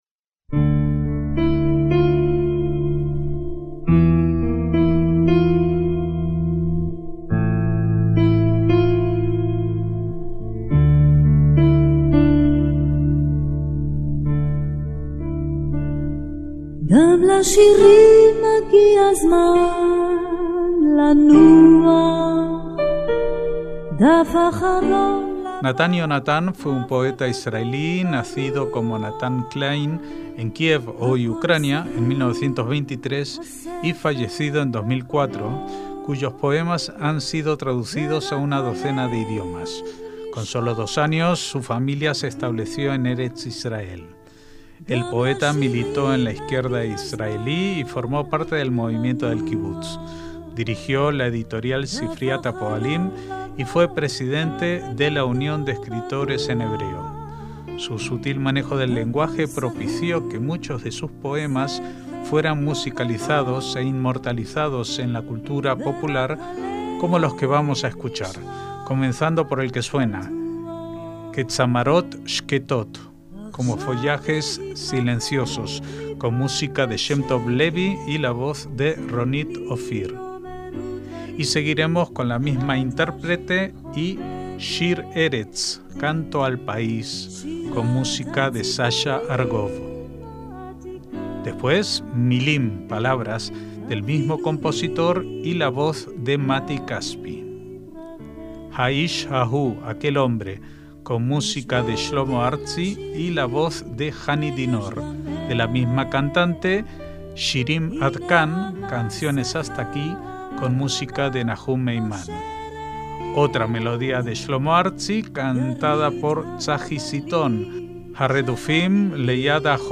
MÚSICA ISRAELÍ - Nathan Yonathan fue un poeta israelí nacido como Nathan Klein en Kiev, hoy Ucrania, en 1923 y fallecido en 2004, cuyos poemas han sido traducidos a una docena de idiomas. Con sólo dos años, su familia se estableció en Eretz Israel.